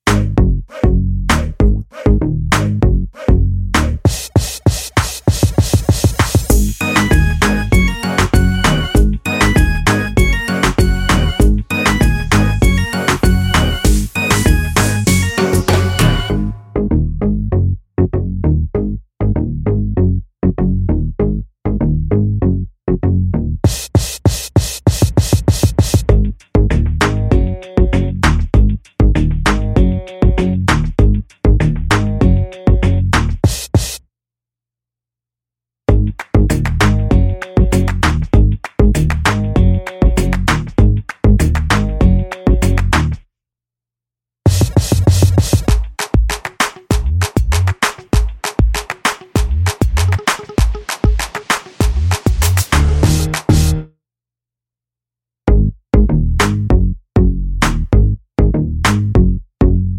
for solo male R'n'B / Hip Hop 3:37 Buy £1.50